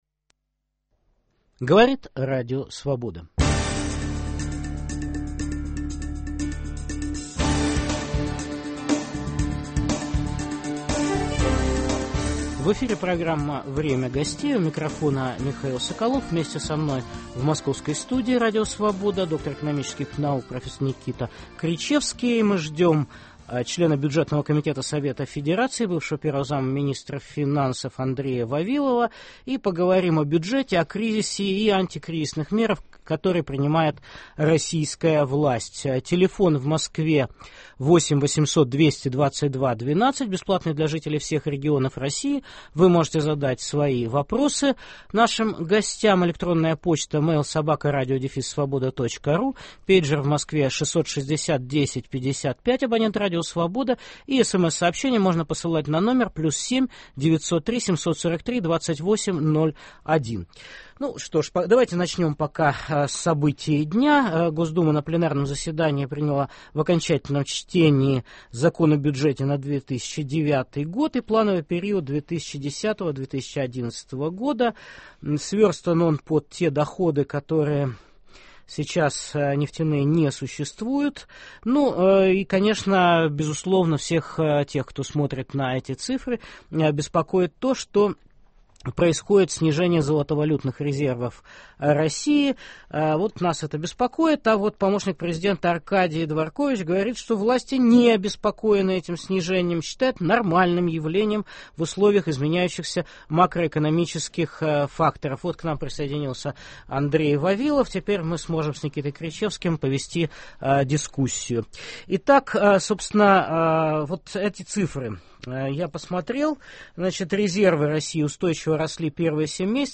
Участвуют член бюджетного комитета Совета федерации РФ, бывший первый зам министра финансов Андрей Вавилов и доктор экономических наук